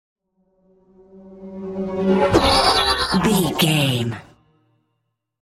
Pass by fast vehicle sci fi
Sound Effects
futuristic
pass by
vehicle